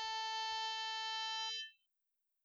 Violín
02_violin.wav